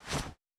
Ball Throw Pitch.wav